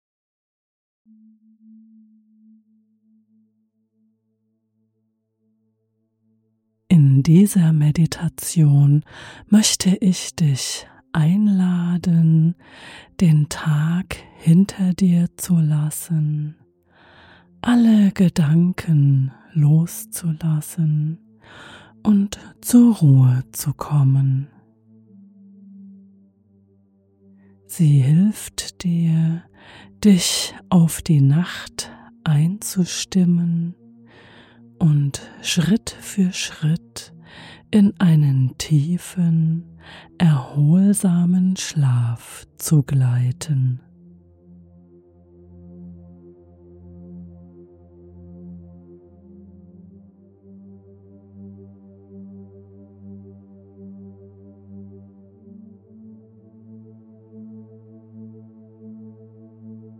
In dieser geführten Meditation wirst du eingeladen, den Tag hinter dir zu lassen und deine Gedanken nach und nach zur Ruhe zu bringen.